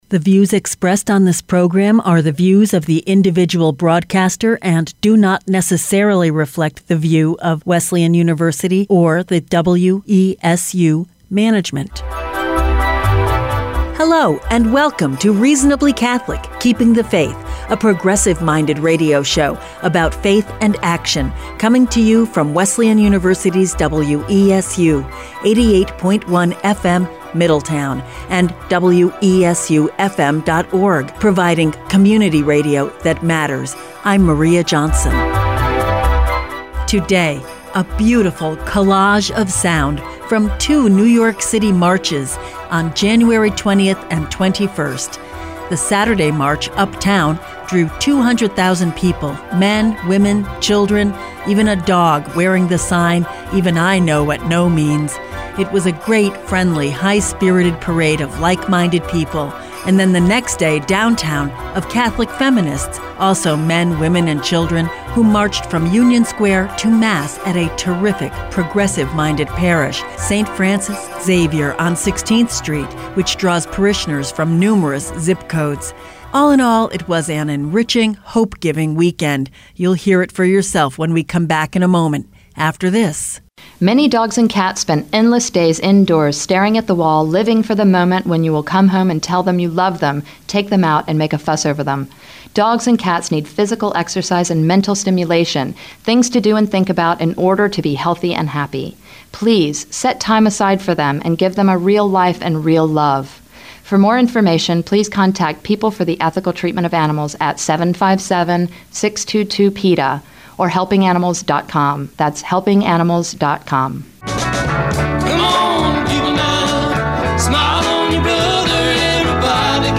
Today: the sound of women — and the men and children who love them — taking to the streets of New York City.